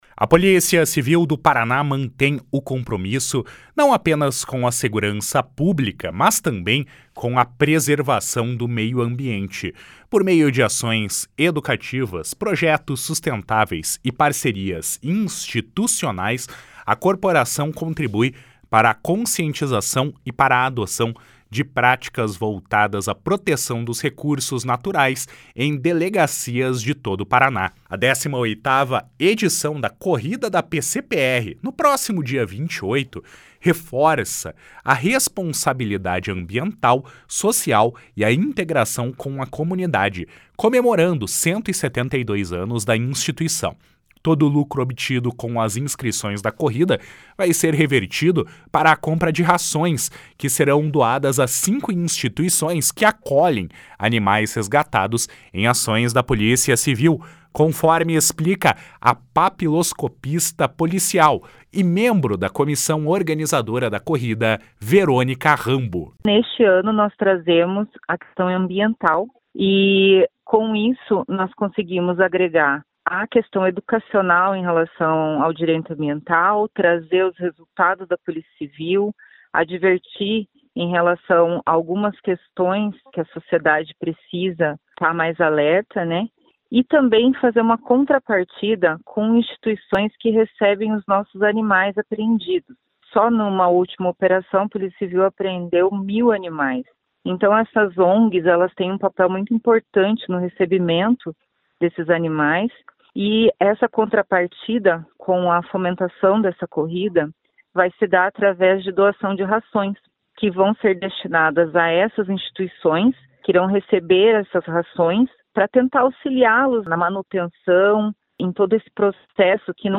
Repórter: